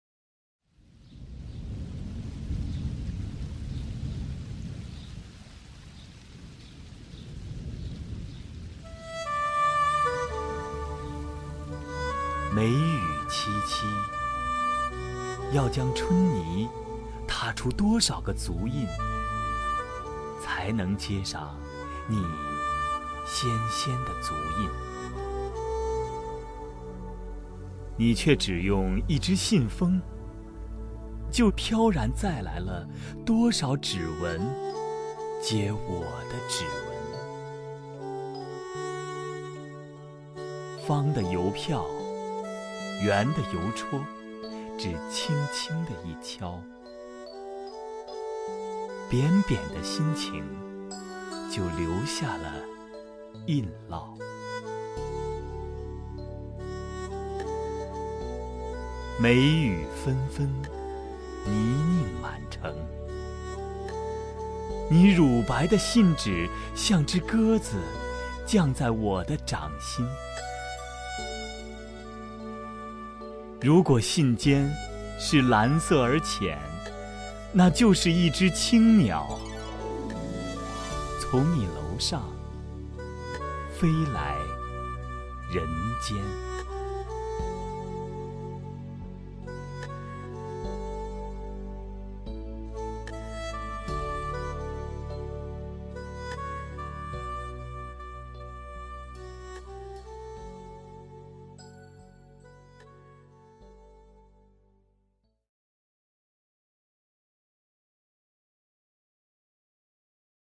首页 视听 名家朗诵欣赏 康辉
康辉朗诵：《梅雨笺》(余光中)